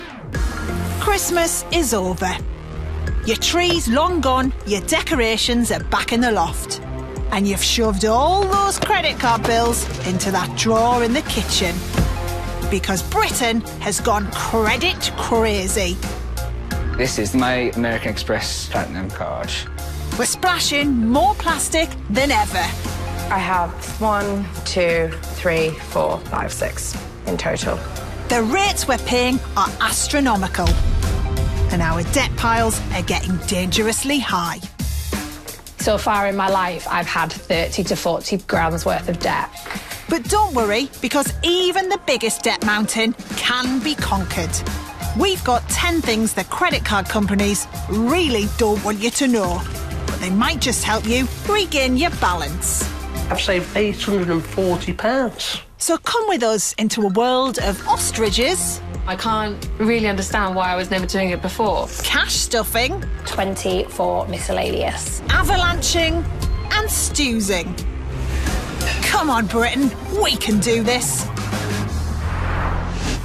Accent:: Northern Yorkshire
Gender:: Female Style:: Authoritative Engaging Friendly Presenter Recognisable Relatable